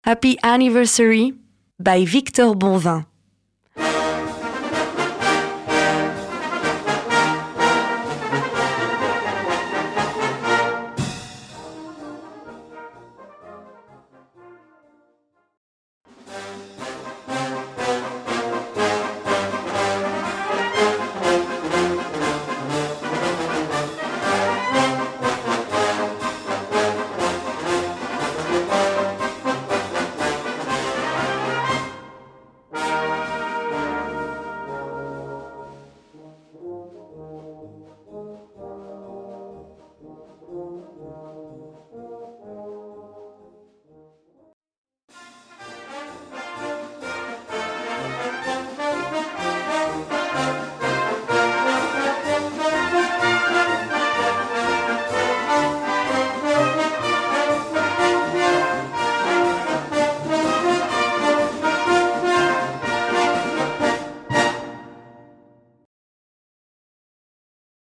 Disponible en version Brass Band et Harmonie
Brass Band
Wind Band (harmonie)
Marches